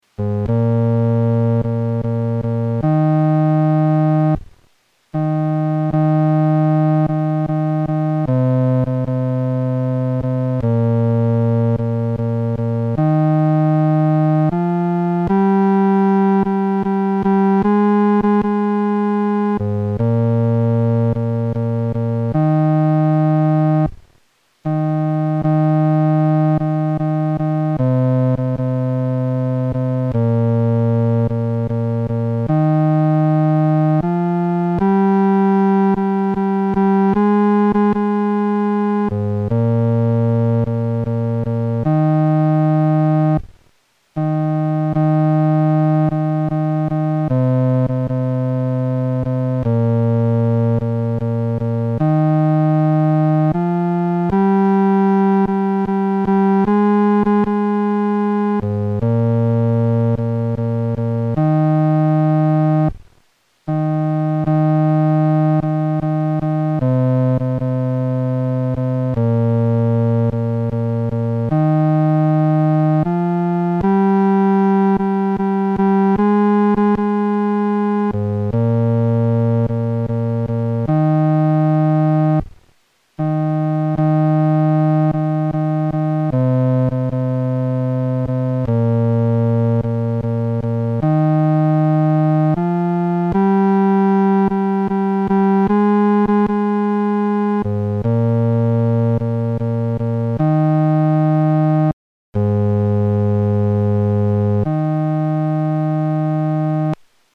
伴奏
男低